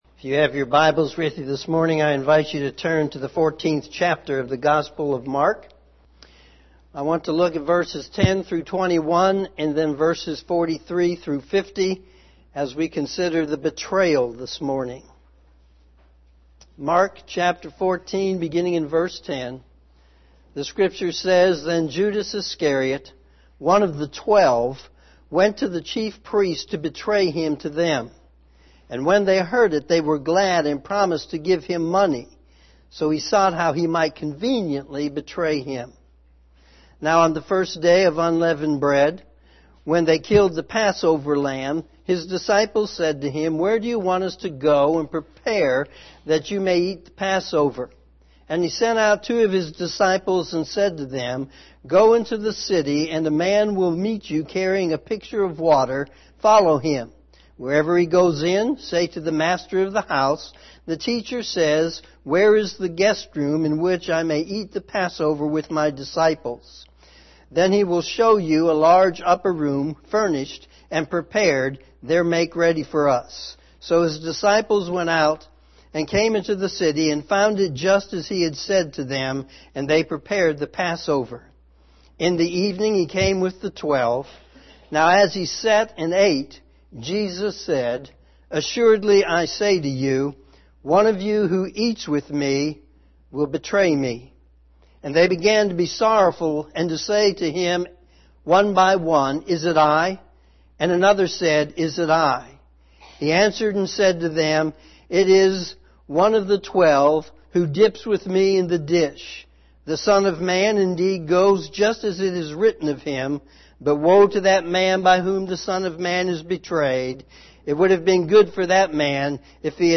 Morning Sermon Mark 14:10-21, 43-50